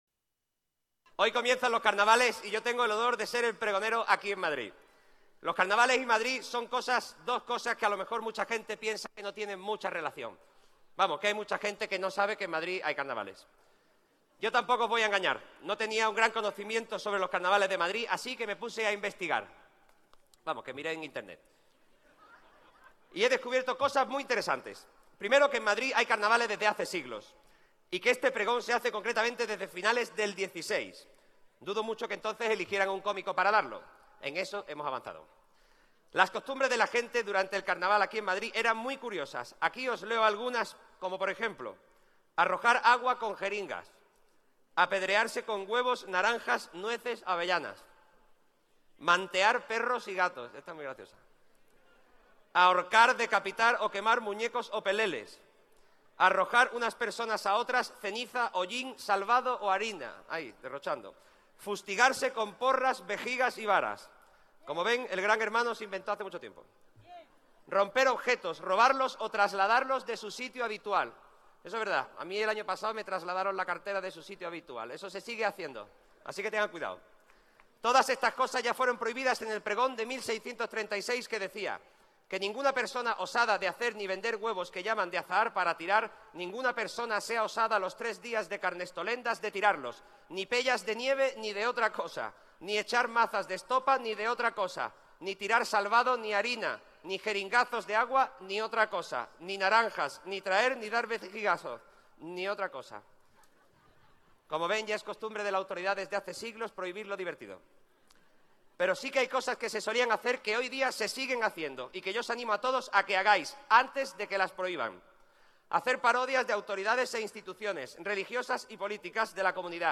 Nueva ventana:Pregón Carnaval Álex O´Dogherty (Audio)